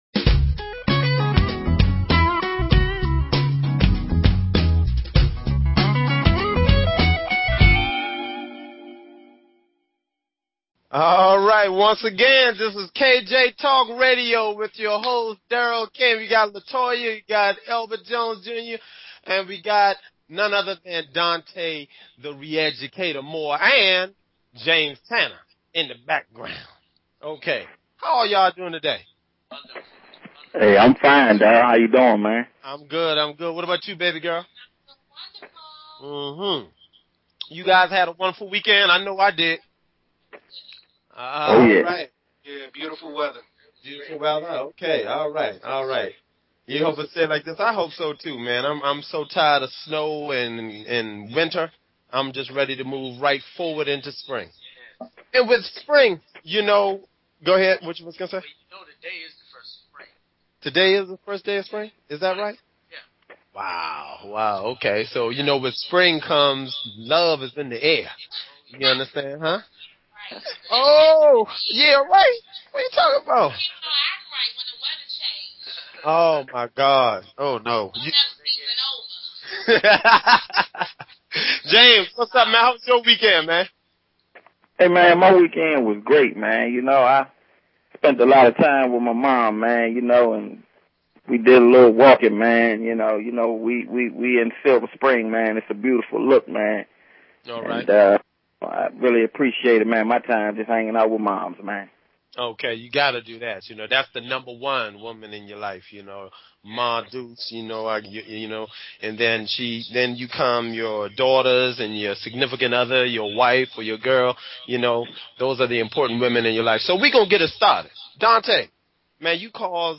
Talk Show Episode, Audio Podcast, KJ_Talk_Radio and Courtesy of BBS Radio on , show guests , about , categorized as
KJ Talk radio is an un opinionated, and open forum which provides a platform for a wide variety of guests, and callers alike.